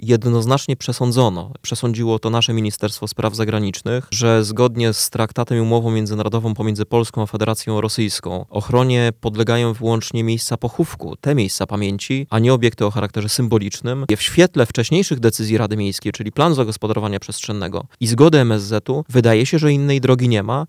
– Ministerstwo Spraw Zagranicznych jednoznacznie przesądziło, że zgodnie z traktatem i umową zawartą z Federacją Rosyjską, ochronie podlegają jedynie miejsca pochówku, a nie obiekty o charakterze symbolicznym. W świetle wcześniejszych decyzji Rady Miejskiej, czyli planu zagospodarowania przestrzennego i zgody MSZ, wydaje się, że innej drogi nie ma – mówił na naszej antenie zastępca prezydenta miasta Rafał Zając.